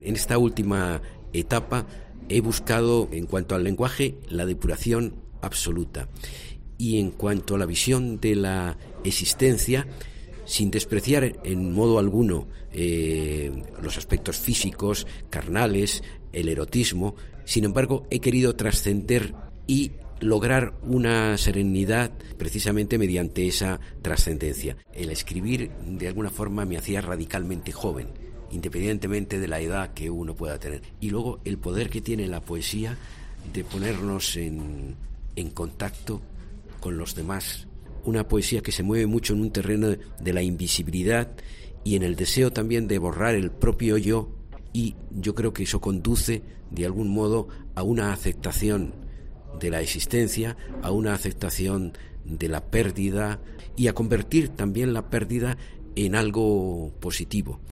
En entrevista con COPE, Lostalé nos cuenta: “he querido borrar el yo en estos versos y acercarme al tú.